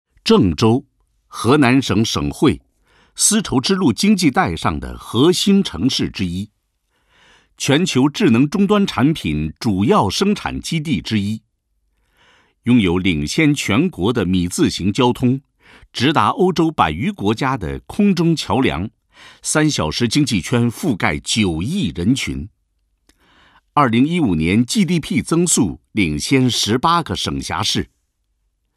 李立宏，男，北京人，著名配音表演艺术家，年度最火纪录片《舌尖上的中国》解说，1986年毕业于北京广播学院播音系。
李立宏_宣传片_企业_正威科技_浑厚.mp3